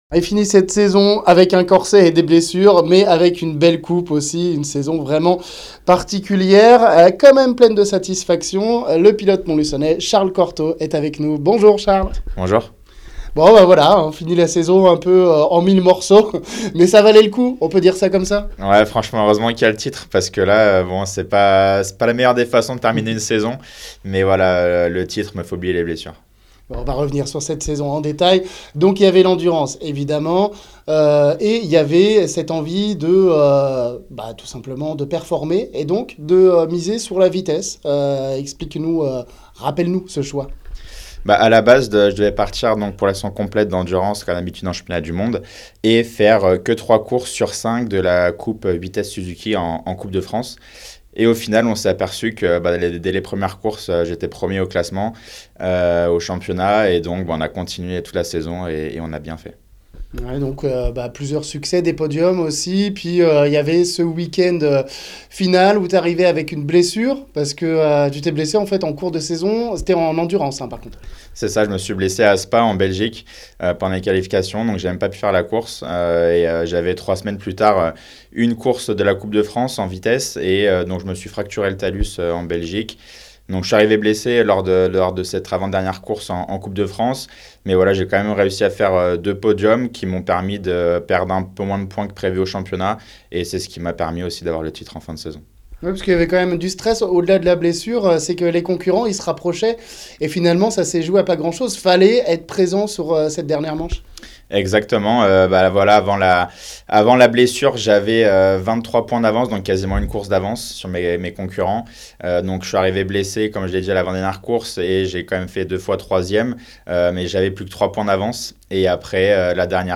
De passage dans les studios de RMB récemment